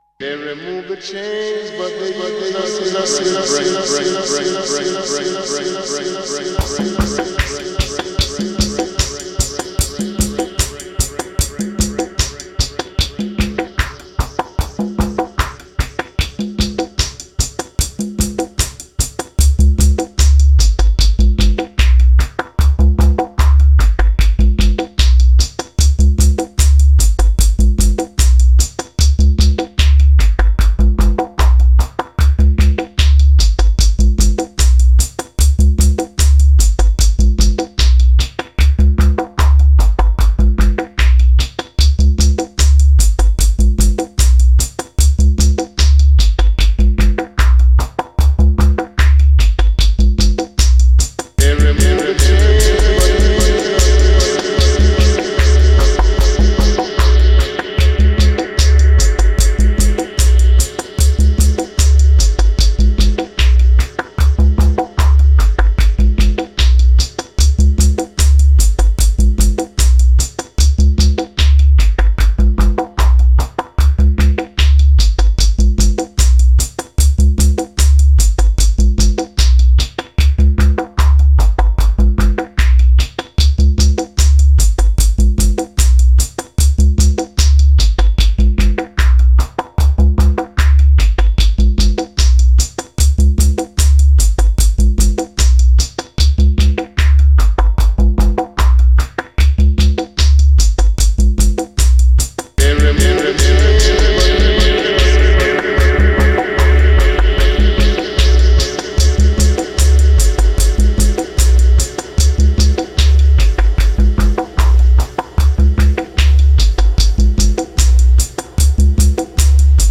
Genre: Dub.